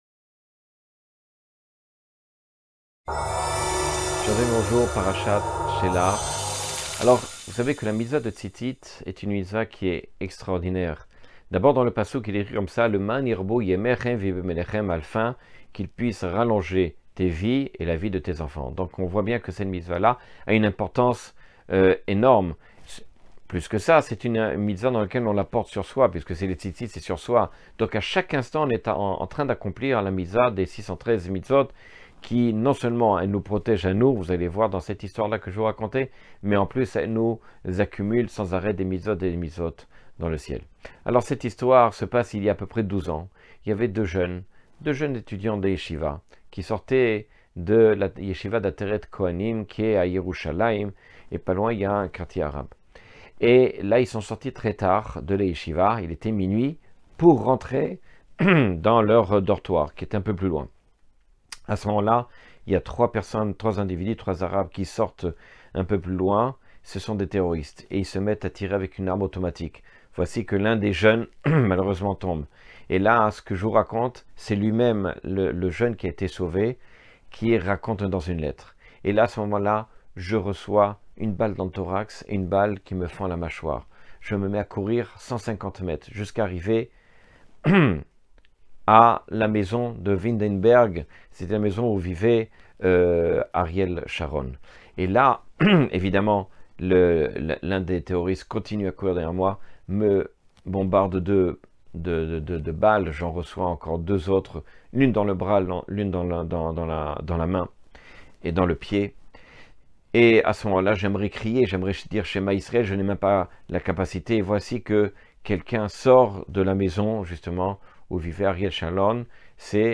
Chiour sur l'éducation à travers la parasha Chela'h Lekha.